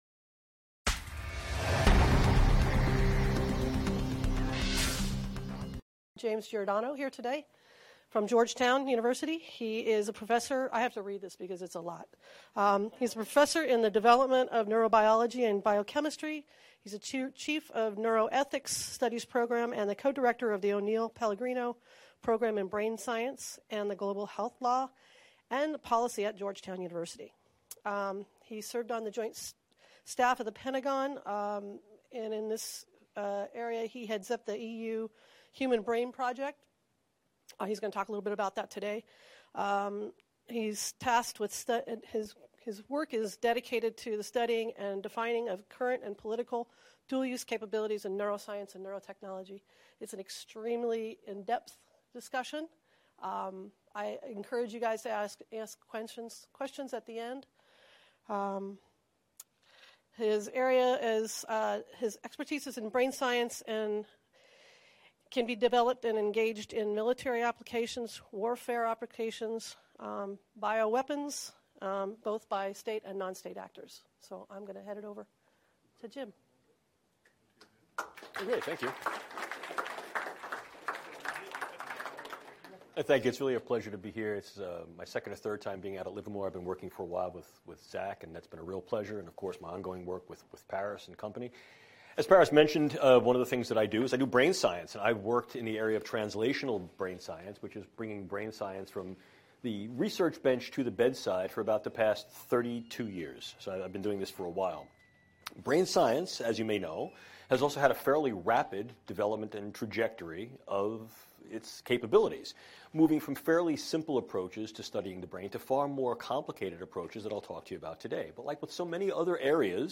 Brain Science from Bench to Battlefield: The Realities – and Risks – of Neuroweapons - CGSR Seminar